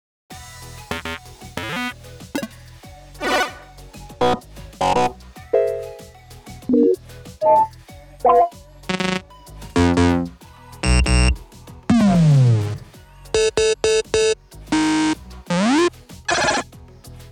FAIL SOUNDS V1.mp3